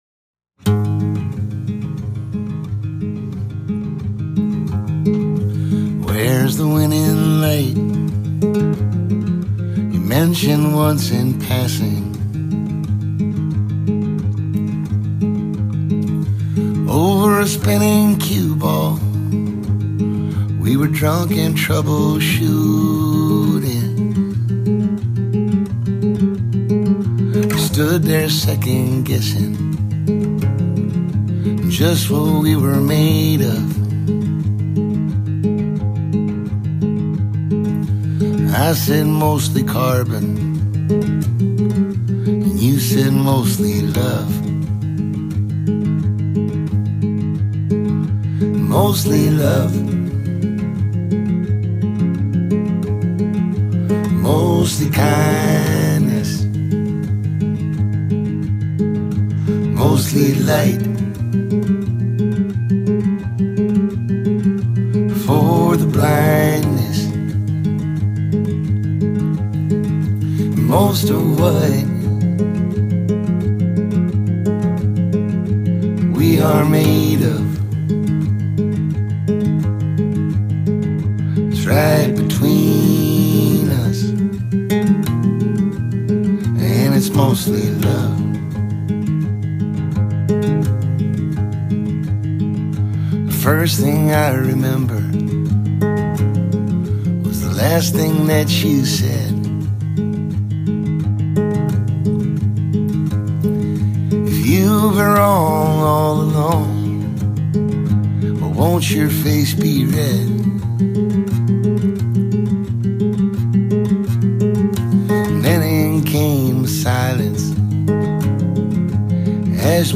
recorded on my Iphone